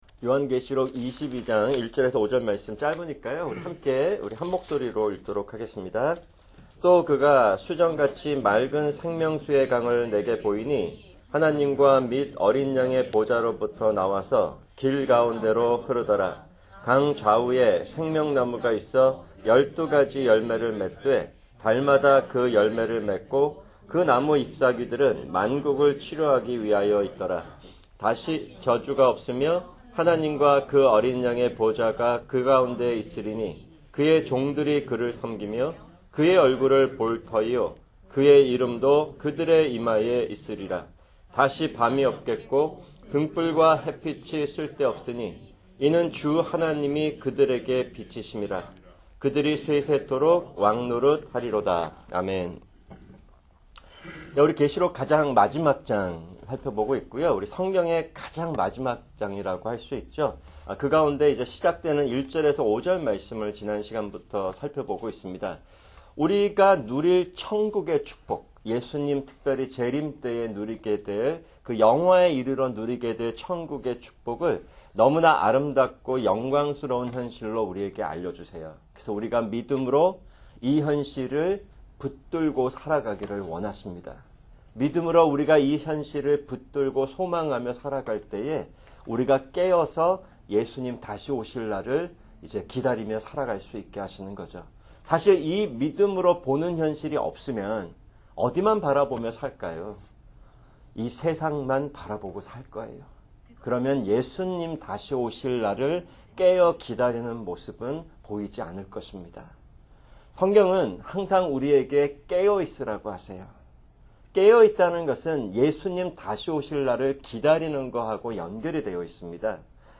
[금요 성경공부] 계시록 22:1-5(2)